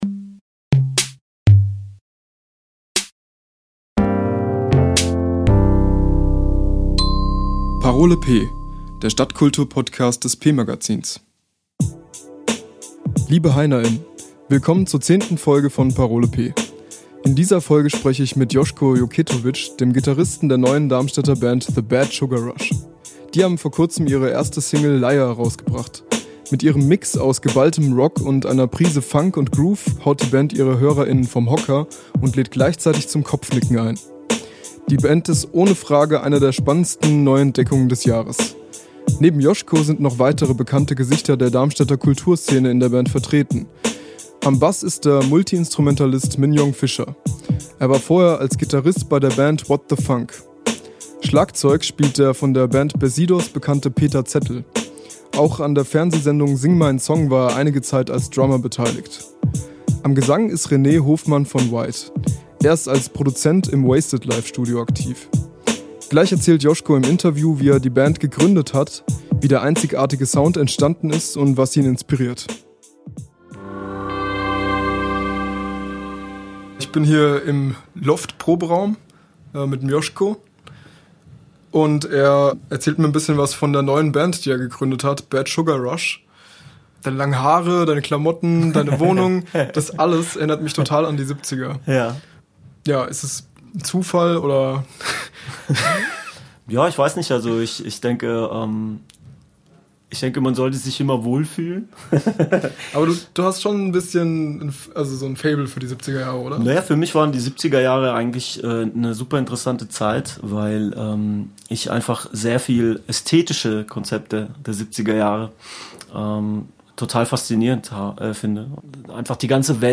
Darmstadts neueste Rockband The Bad Sugar Rush im Interview!